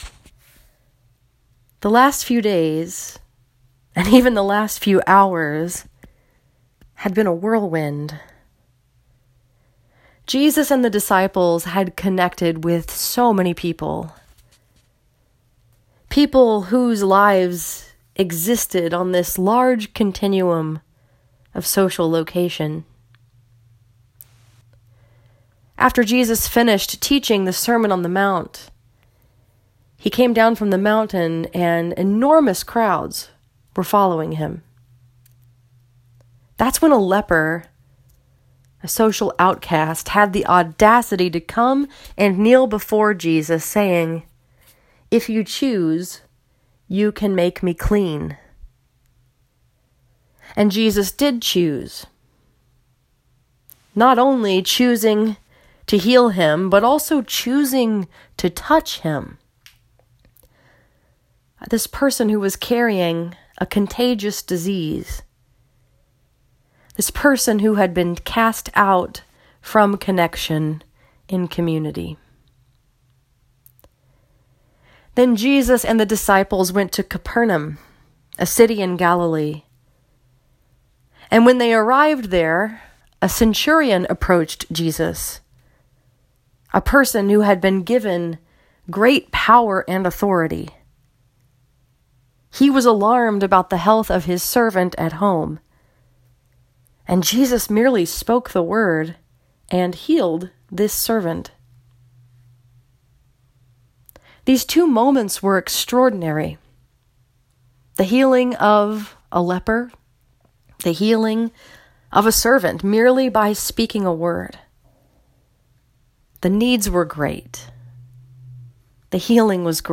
This sermon was preached at First Presbyterian Church in Troy, Michigan and was focused upon the story that is told in Matthew 8:23-27.